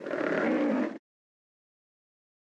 PixelPerfectionCE/assets/minecraft/sounds/mob/polarbear_baby/idle1.ogg at 6d6f48947e7ae03e402980b2510fdc3b2fb8634b